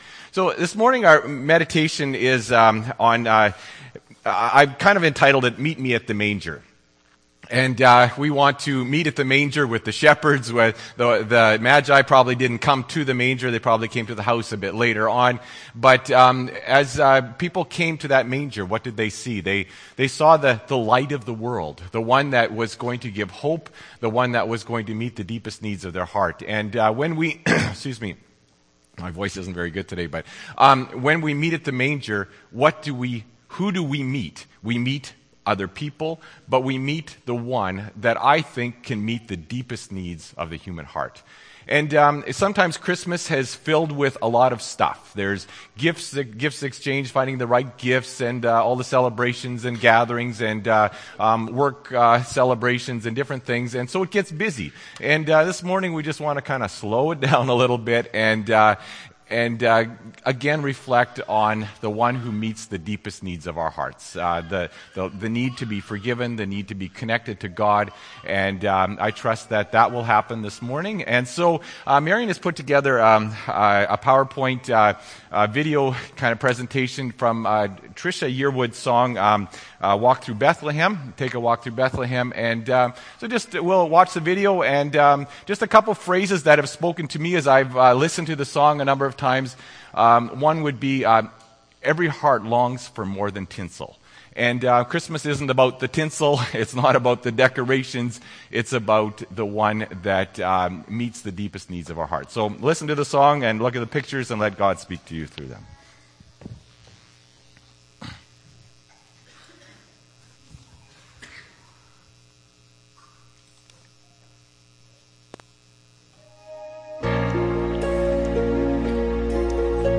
Dec. 25, 2014 – Sermon